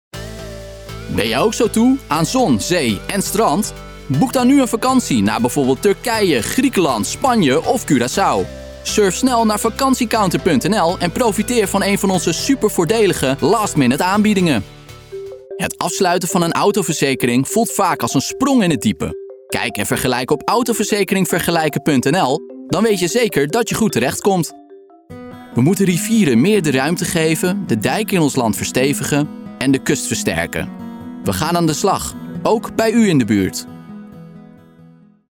moedertaal: nederlands engels mannenstem levering per e-mail mogelijk ervaring:gevorderde
klankleeftijd:klankleeftijd 15-25klankleeftijd 25-40
Ik spreek het liefst vlotte en commerciële teksten in. Mijn stem wordt omschreven als 'jong geluid', dus doe daar je voordeel mee.